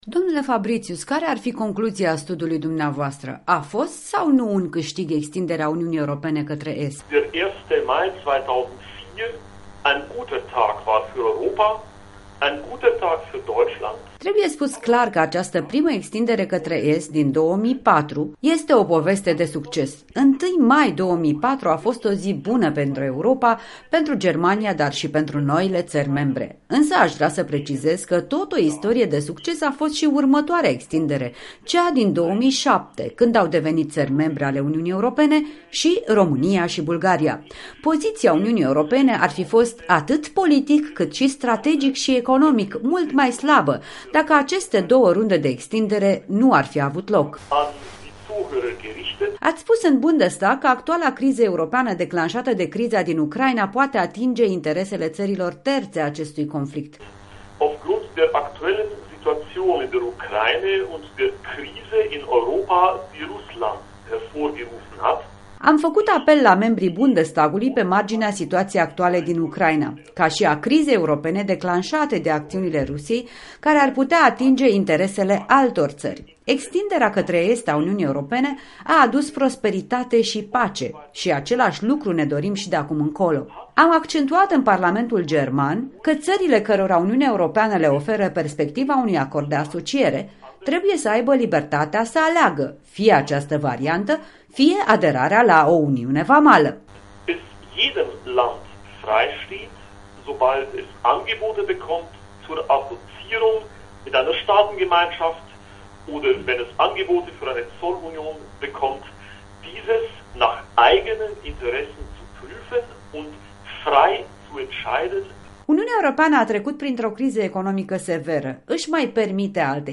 În direct de la Strasbourg: un interviu cu europarlamentarul Bernd Fabritius